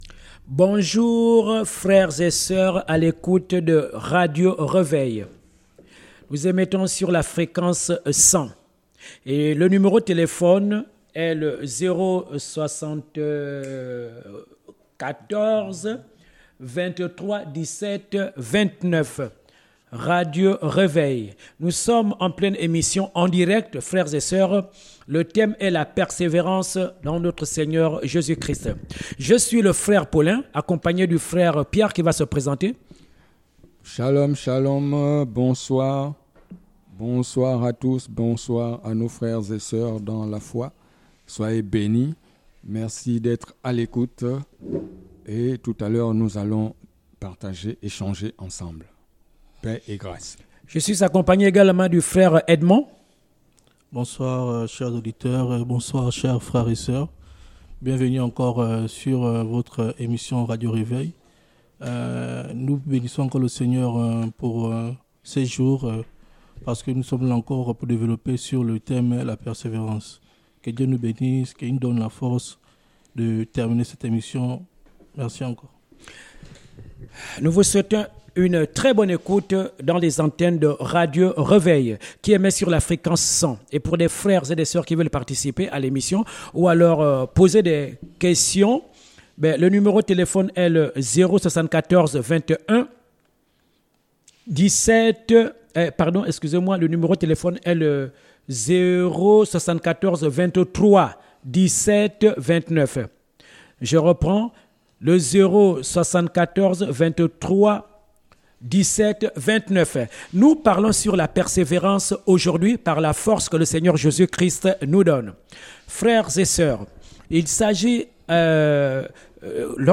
enseignement